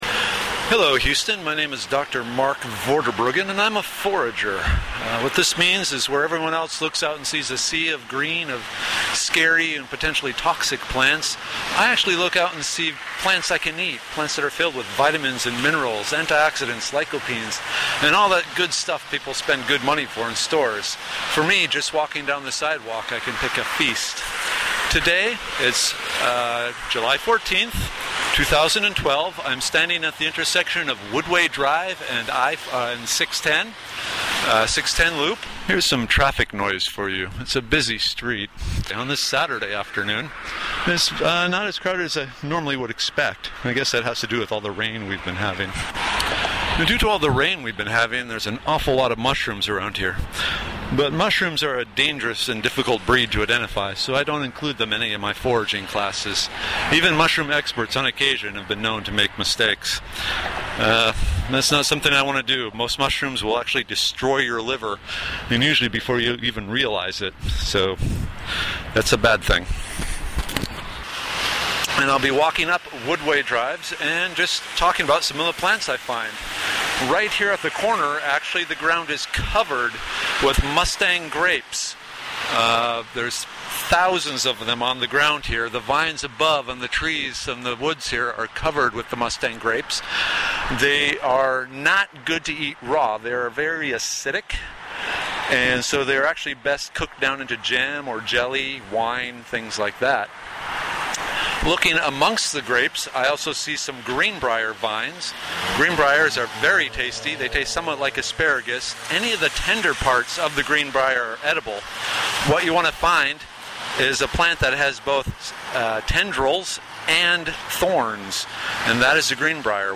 This walking tour reveals some of the many edible wild plants one can find during the summer in Houston.